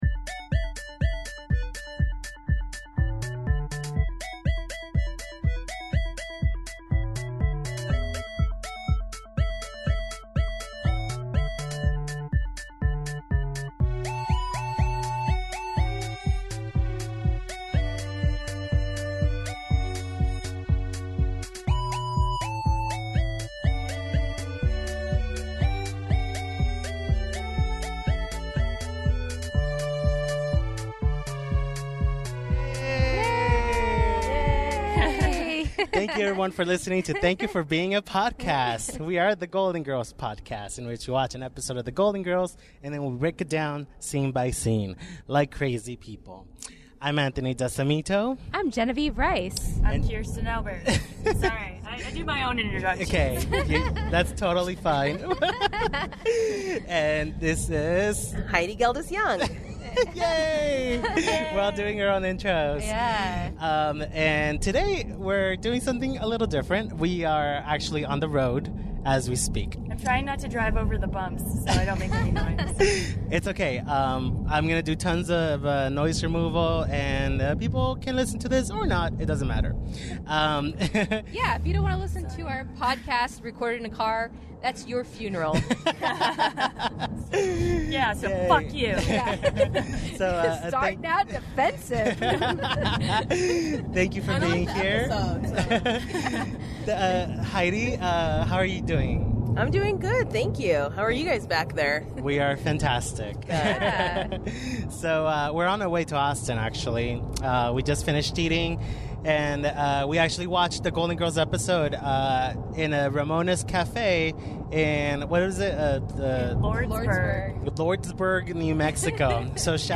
This was a drivecast, which means we recorded this while we were driving to Austin through the middle of New Mexico. We stopped at a diner in Lordsburg and watched the one where the girls try to get Bob Hope to headline their talent show fundraiser.